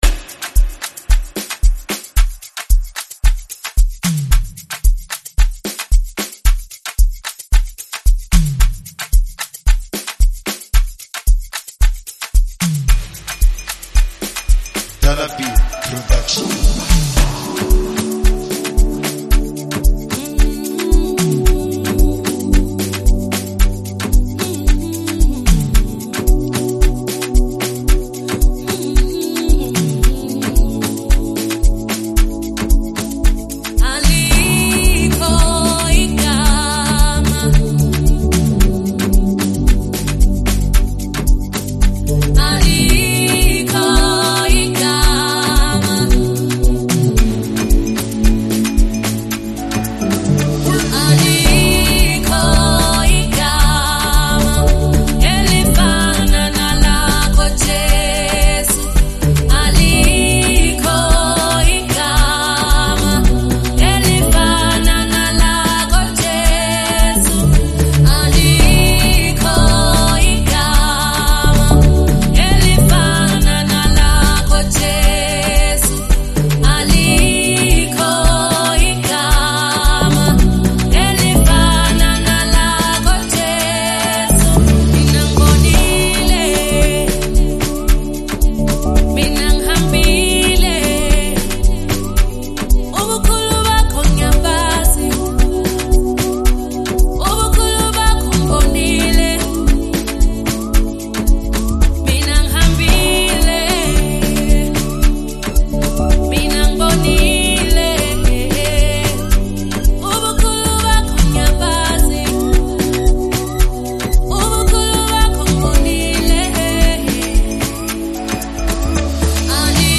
Genre: Afro House
is a powerful Afro House gospel anthem
featuring the soulful vocals